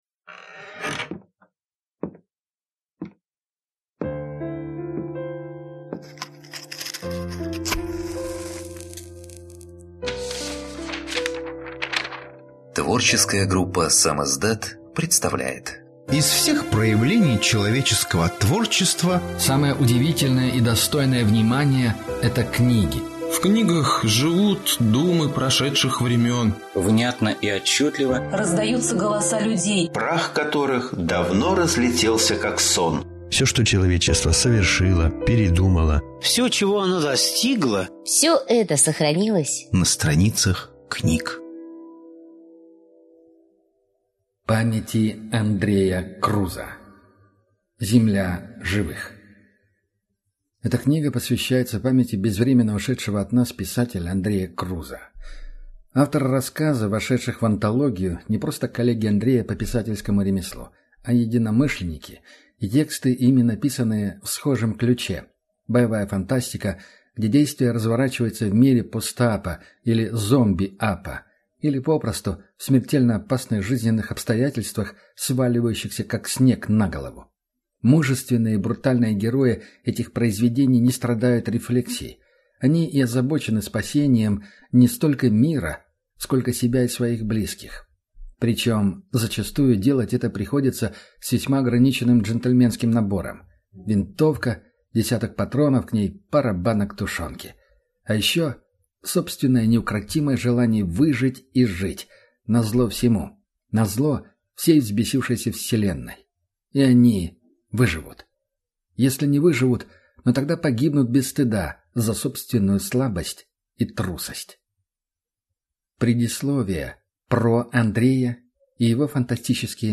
Аудиокнига Земля живых (сборник) | Библиотека аудиокниг